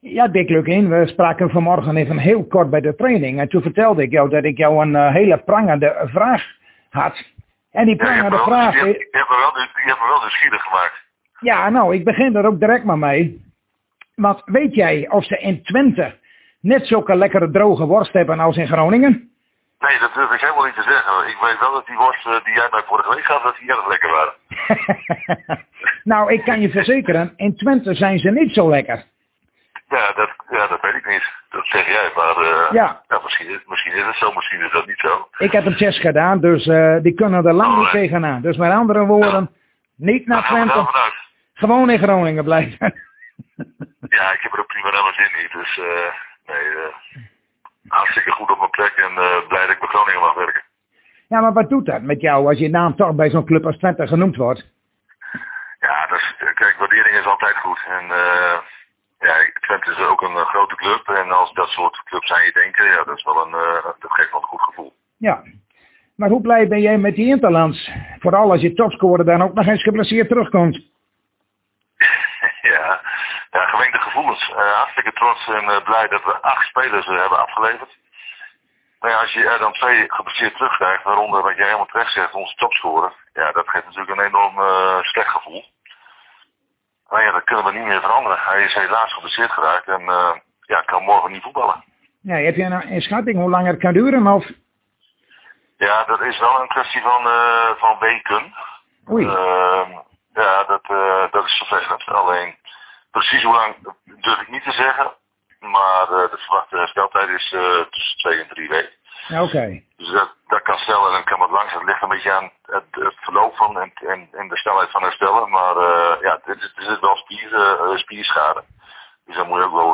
Zojuist spraken wij weer met trainer Dick Lukkien en dat werd een leuk gesprek waarin we spraken over droge worst, over de wedstrijd tegen FC Utrecht en over de internatioals van FC Groningen.